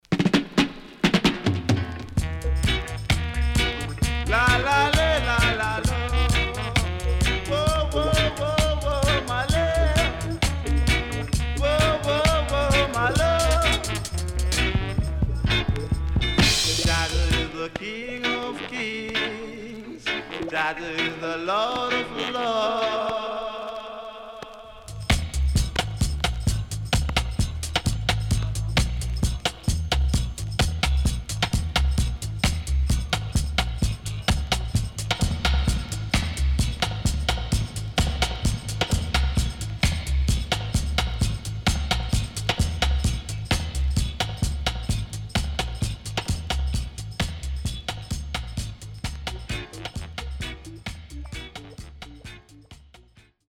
Killer Stepper Vocal & Dubwise
SIDE A:所々チリノイズがあり、少しプチノイズ入ります。